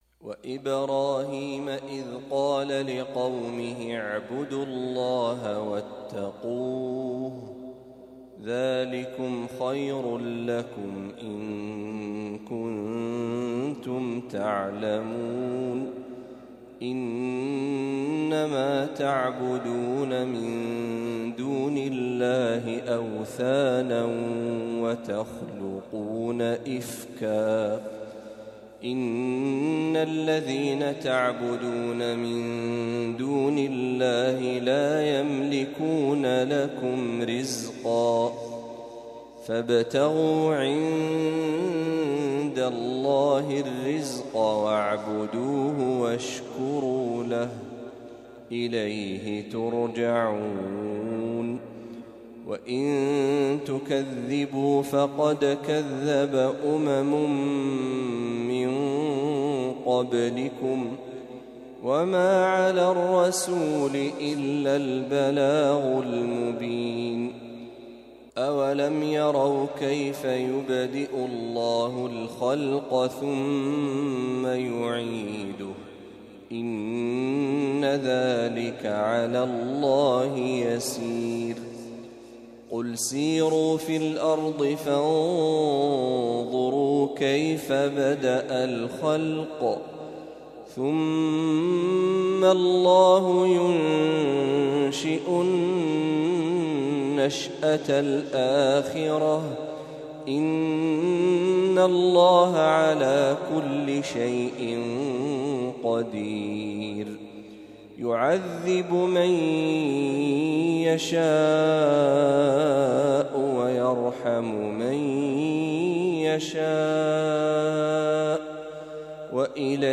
تلاوة من سورة العنكبوت | فجر الخميس ٥ محرم ١٤٤٦هـ > 1446هـ > تلاوات الشيخ محمد برهجي > المزيد - تلاوات الحرمين